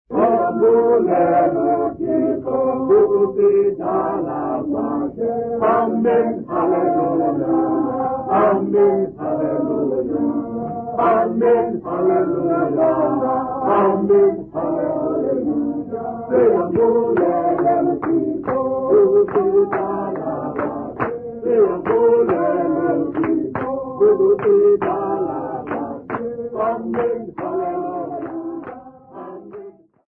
Church music South Africa
Hymns, Xhosa South Africa
Africa South Africa Lumko, Eastern Cape sa
field recordings
An unaccompanied traditional hymn